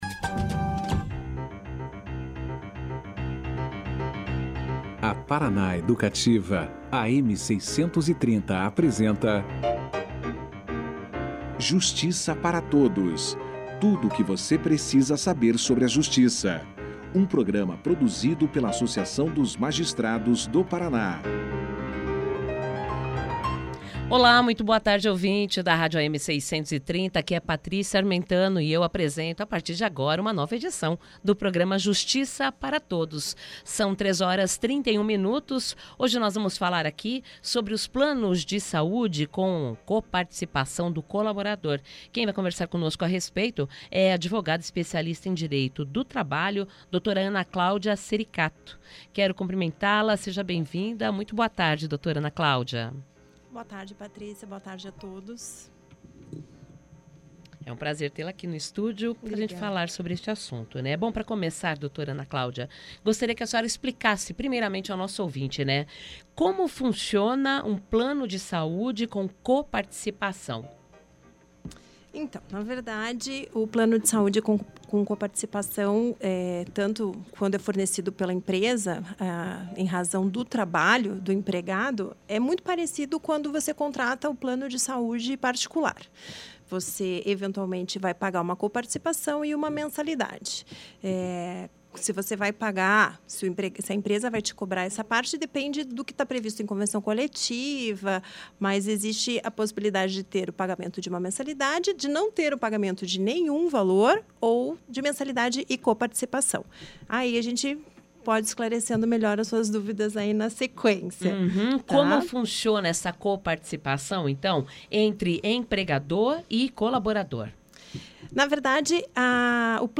Os detalhes da entrevista você acompanha agora.